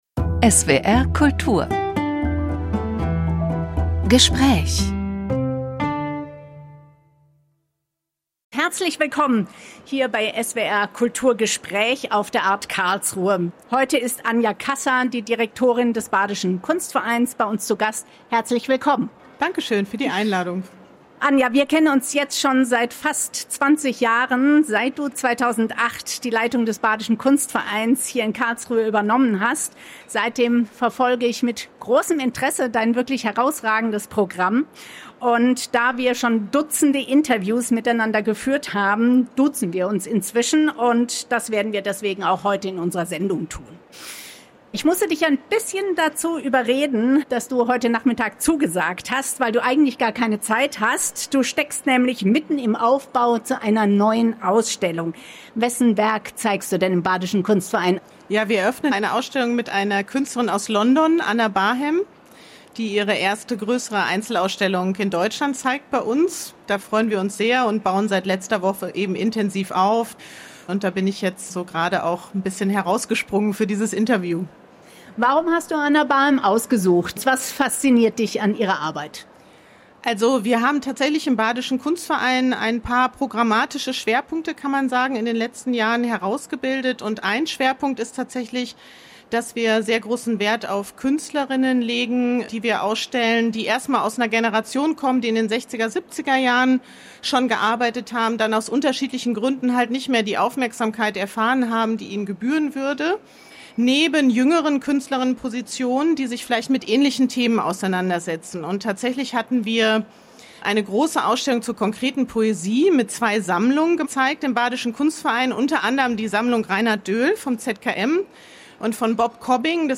(Öffentliche Veranstaltung vom 7. Februar 2026 bei der art karlsruhe)